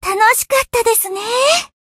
贡献 ） 分类:蔚蓝档案语音 协议:Copyright 您不可以覆盖此文件。
BA_V_Hanako_Battle_Victory_1.ogg